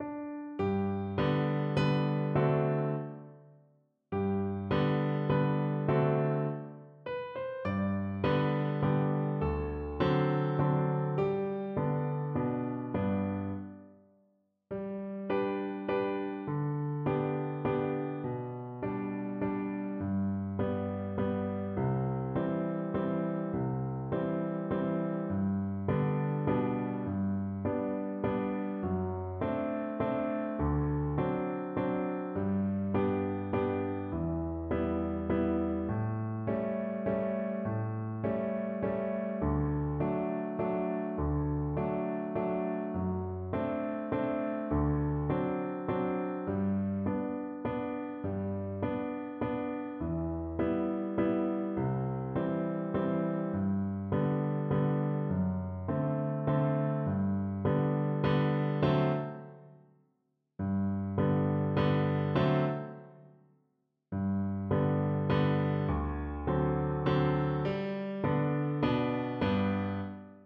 Free Sheet music for Soprano (Descant) Recorder
One in a bar . = c.54
3/4 (View more 3/4 Music)
D6-D7
Pop (View more Pop Recorder Music)